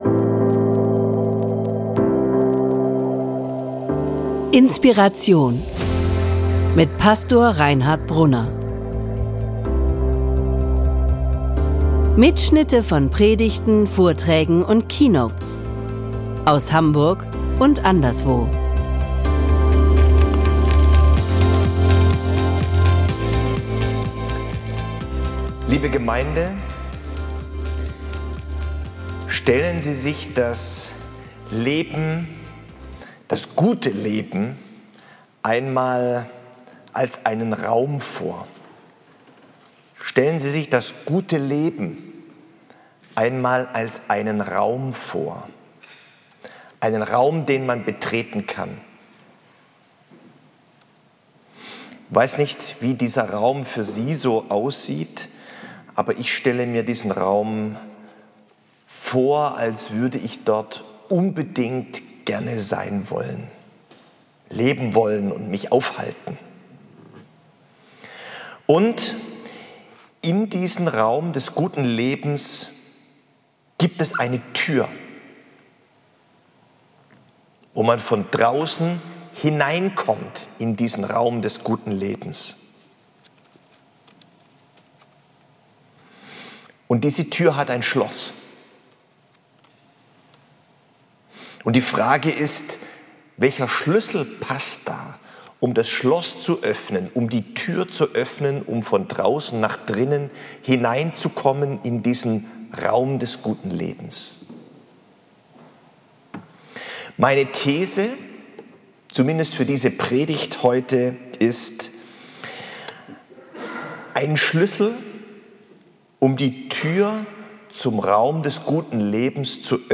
Welches ist der Schüssel zum Raum des guten Lebens? Meine These in dieser Predigt vom Herbst 2024: Dankbarkeit ist der Schüssel zum Raum des guten Lebens - vielleicht sogar der Generalschlüssel...